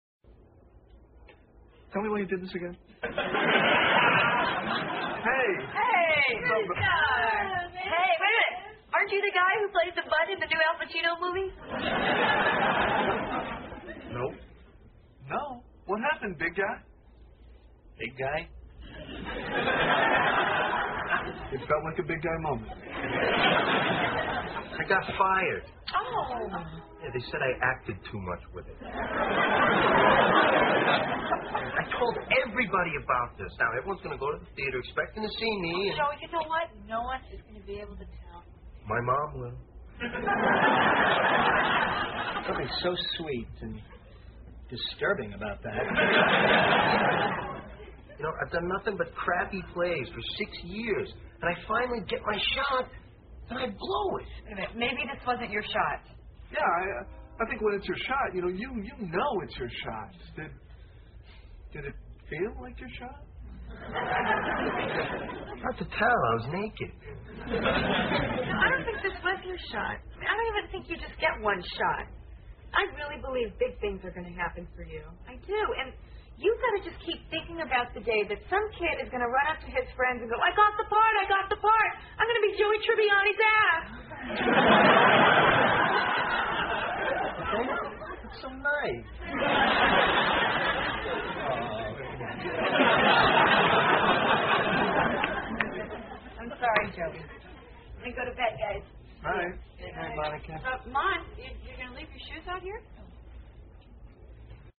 在线英语听力室老友记精校版第1季 第72期:屁股秀(12)的听力文件下载, 《老友记精校版》是美国乃至全世界最受欢迎的情景喜剧，一共拍摄了10季，以其幽默的对白和与现实生活的贴近吸引了无数的观众，精校版栏目搭配高音质音频与同步双语字幕，是练习提升英语听力水平，积累英语知识的好帮手。